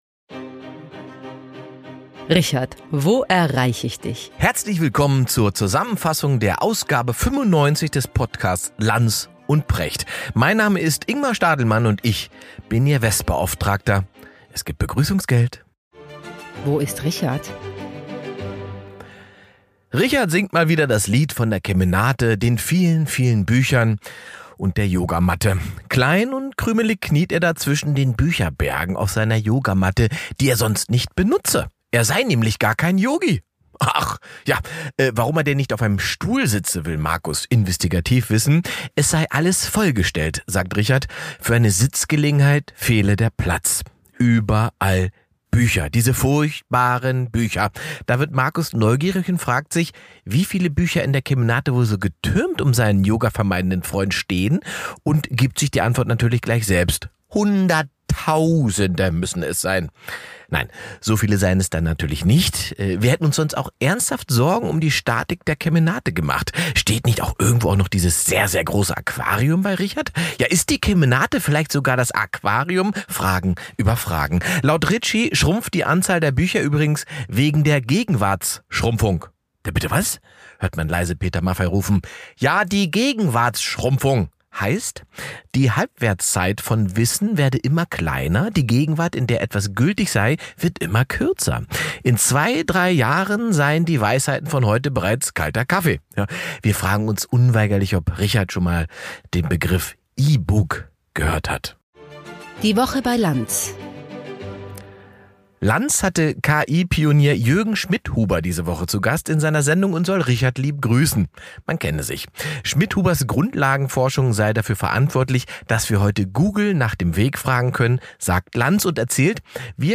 In dieser Podcast-Episode fasst Ingmar Stadelmann die Ausgabe 95 von Lanz & Precht zusammen.